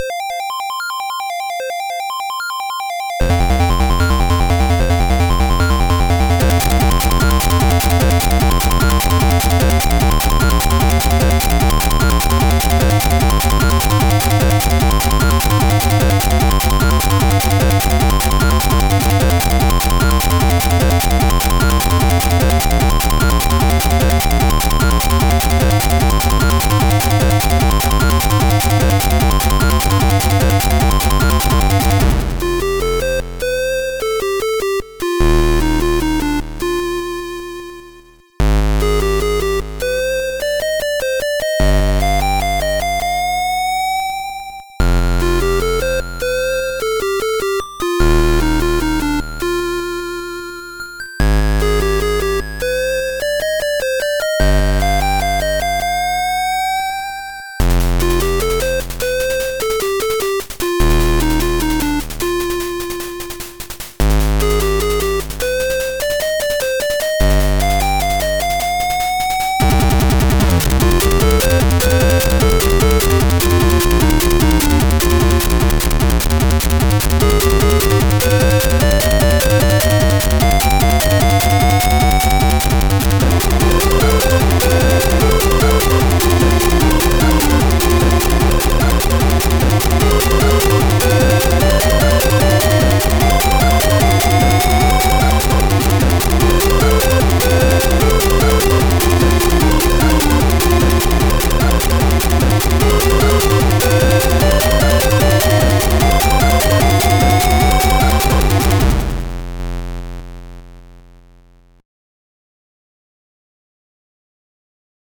ZX Spectrum + AY
• Sound chip AY-3-8912 / YM2149